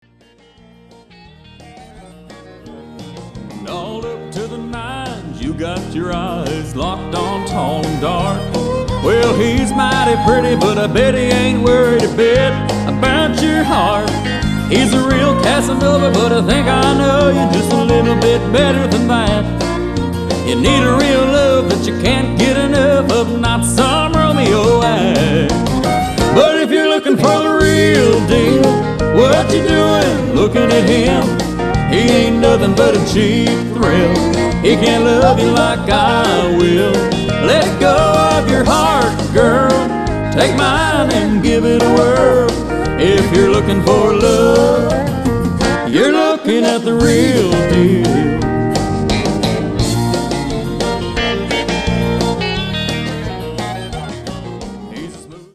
11 Country Songs